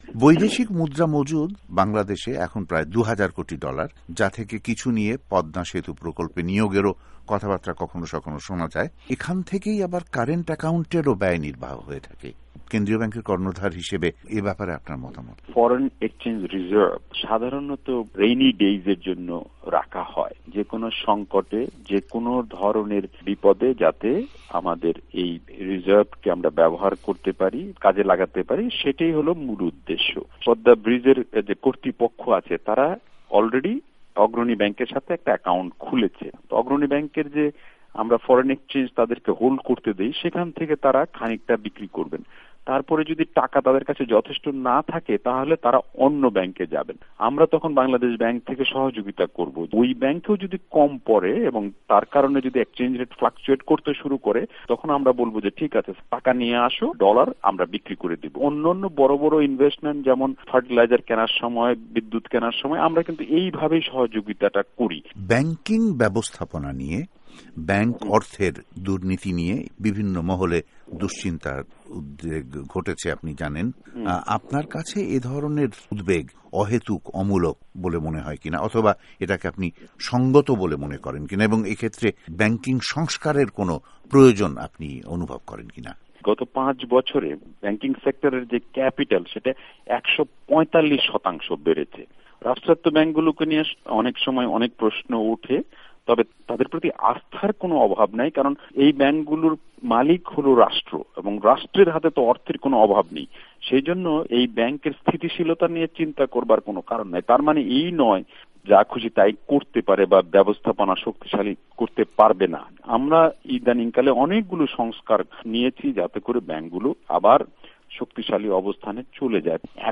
বাংলাদেশ ব্যাঙ্কের গভর্ণর ডক্টর আতিয়ুর রহমানের সাক্ষাত্কার